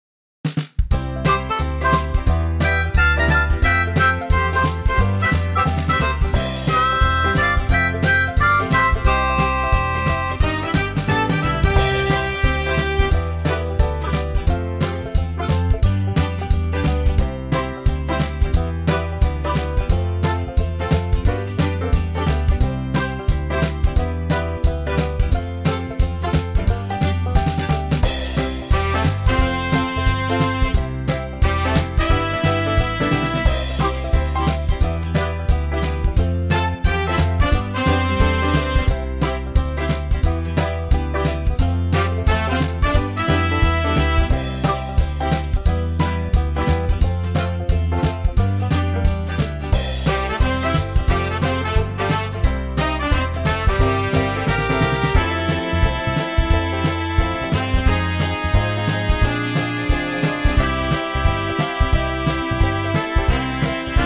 Unique Backing Tracks
key - C - vocal range - A to A
Suitable for female ranges and high male ranges.